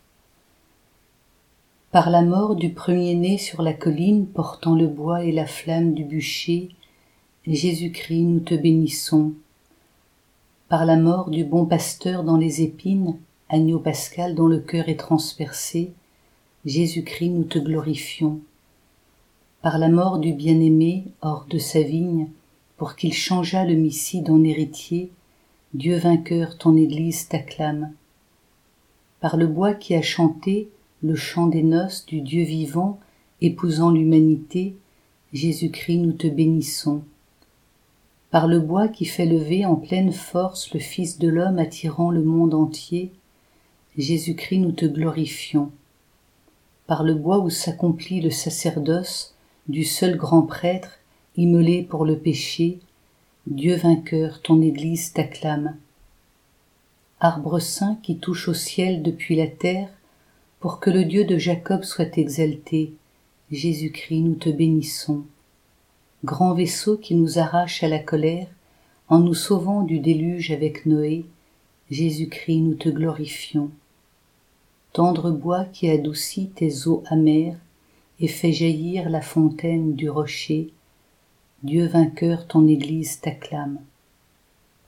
La Croix glorieuse - Chorale Paroissiale du Pôle Missionnaire de Fontainebleau
Hymne-Par-la-mort-du-premier-ne.mp3